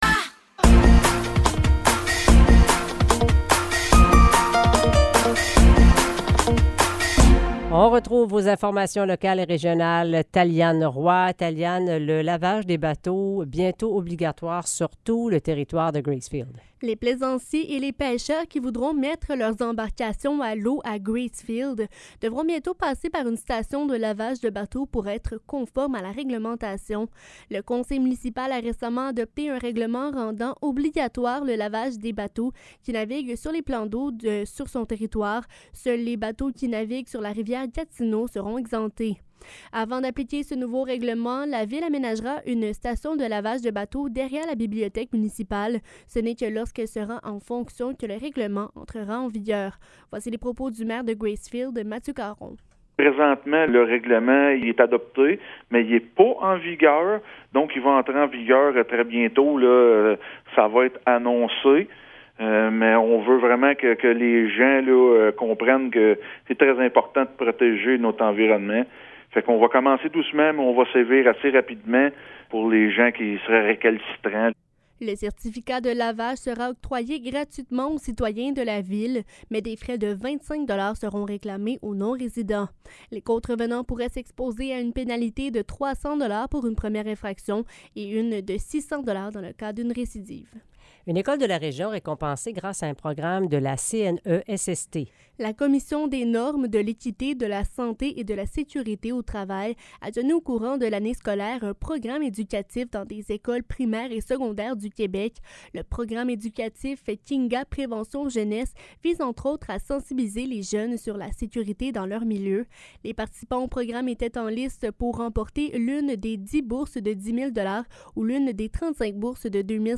Nouvelles locales - 27 juin 2023 - 17 h